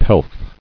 [pelf]